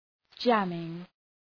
Προφορά
{‘dʒæmıŋ}